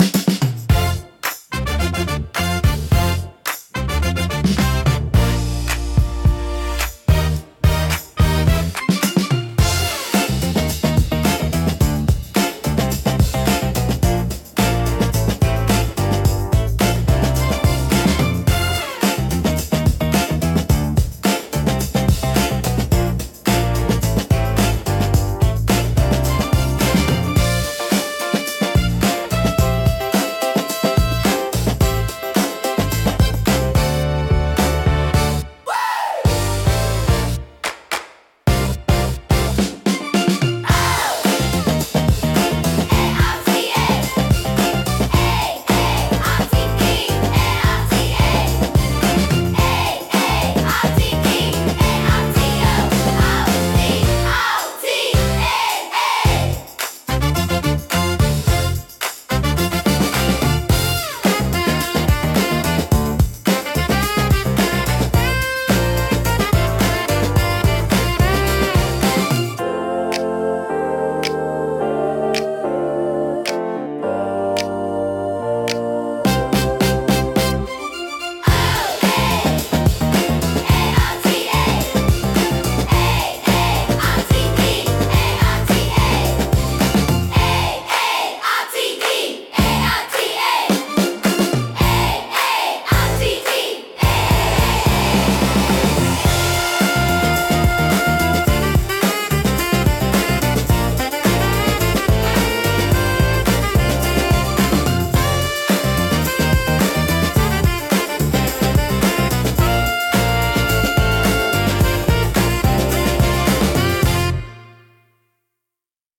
親しみやすいサウンドとポップな雰囲気、明るく感情的な楽曲が多いです。
聴く人の気分を前向きにし、懐かしくも活気ある空間を生み出します。心に残るハーモニーと温かさが魅力のジャンルです。